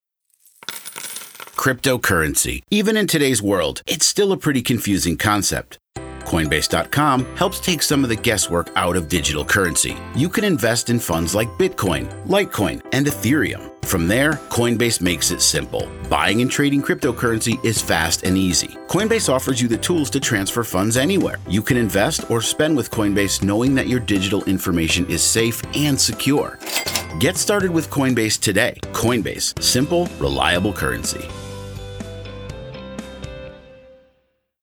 A Professional American Male Voice Actor With A Smooth & Vibrant Delivery
Radio Ad For Coinbase